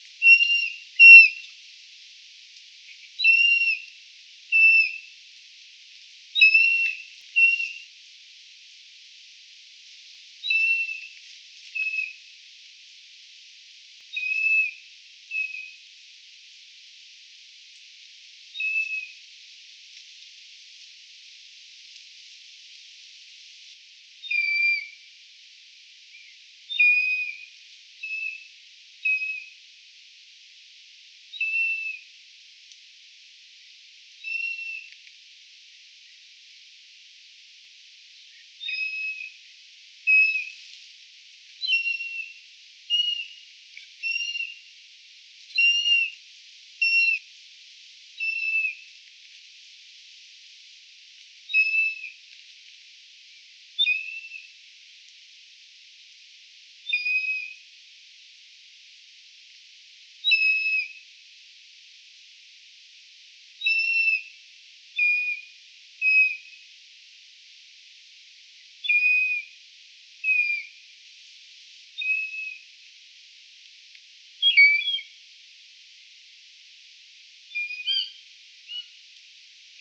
E 11,2663° - ALTITUDE: +300 m. - VOCALIZATION TYPE: flight calls.
Note the more whistled and less nasal quality of these calls compared to those in the previous recordings. - MIC: (Built in unidirectional microphone of Tascam DR100 mkIII)